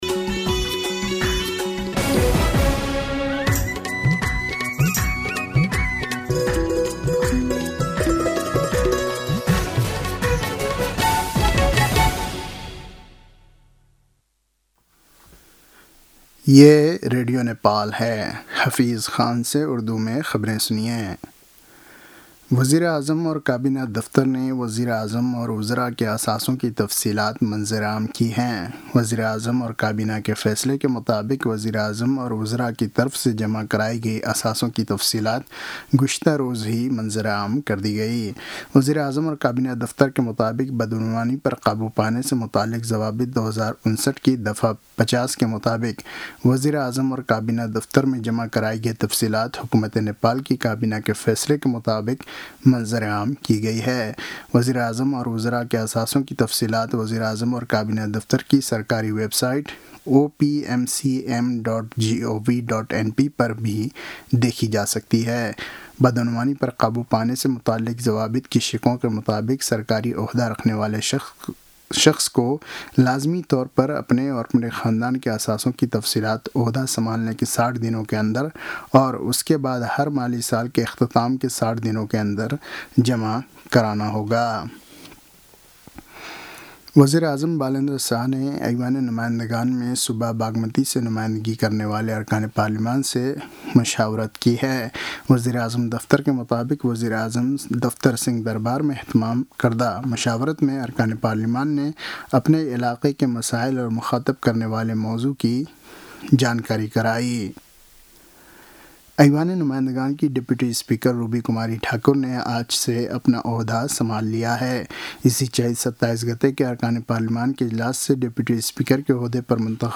An online outlet of Nepal's national radio broadcaster
उर्दु भाषामा समाचार : ३० चैत , २०८२